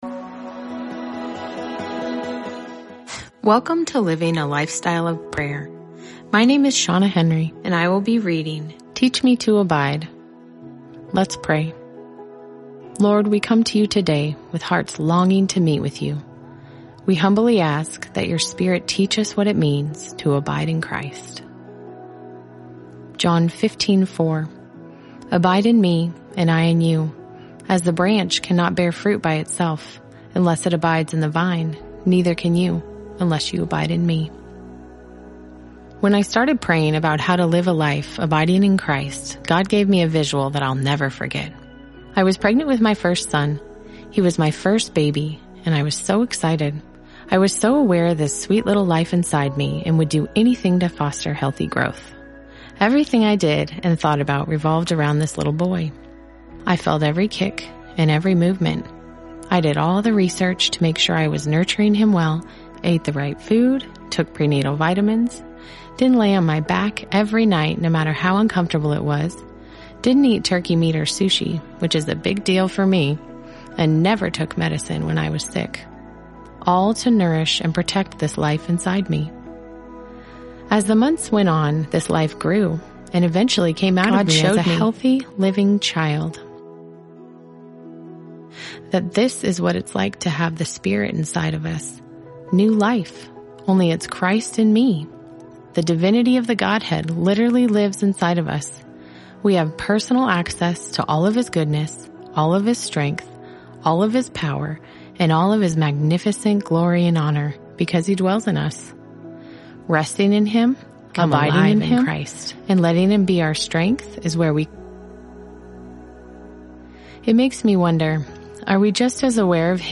Living a Lifestyle of Prayer: 30-Day Audio Devotional for Moms